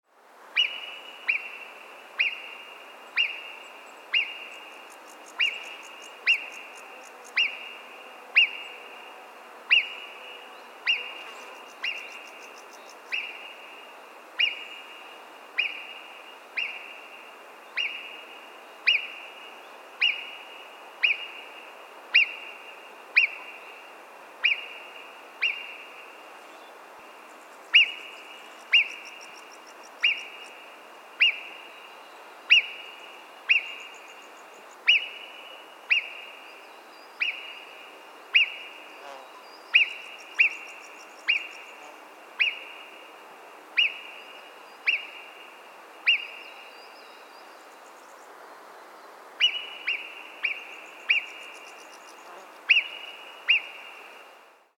Genre: Animal Sound Recording.
NAJUversum_Gebirge_Murmeltier_Sound_Warnrufe_Tierstimmenarchiv_Museum_fuer_Naturkunde_Berlinshort.mp3